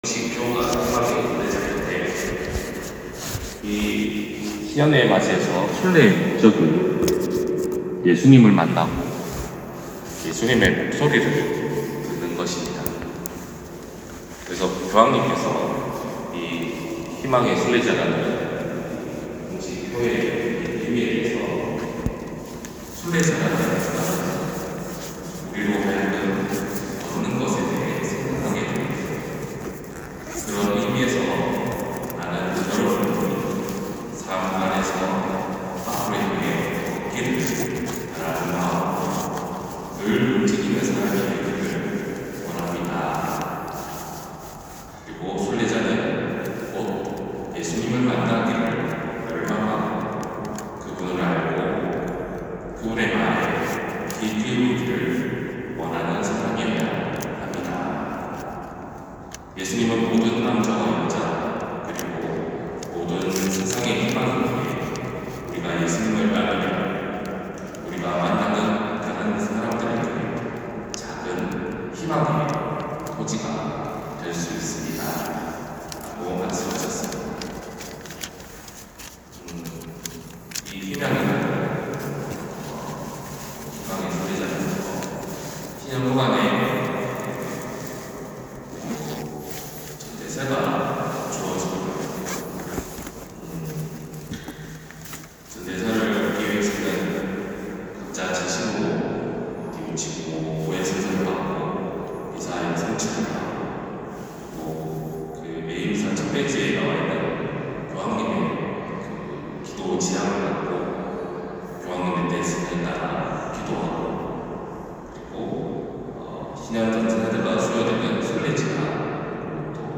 250118신부님 강론말씀